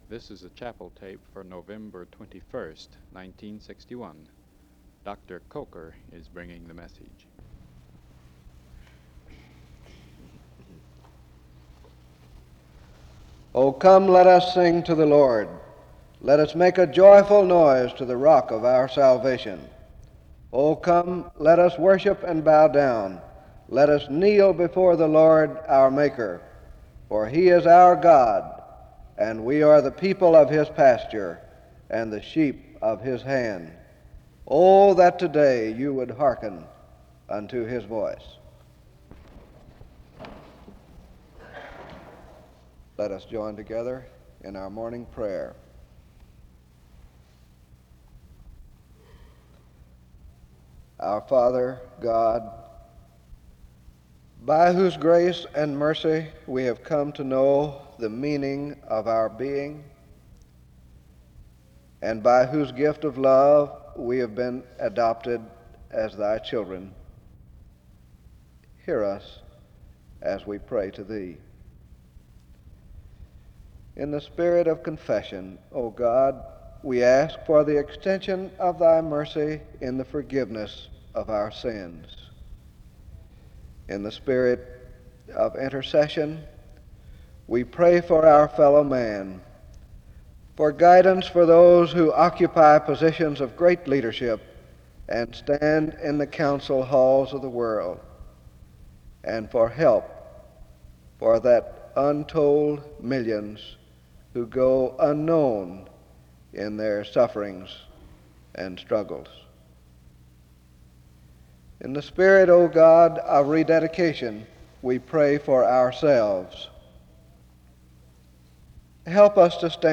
Download .mp3 Description The service begins with the reading of excerpts from Psalm 95 (00:00-00:49) and prayer (00:50-03:04).
He closes in prayer (22:46-23:15), and the service ends with instrumental music (23:16-24:18).
Subject Church--Biblical teaching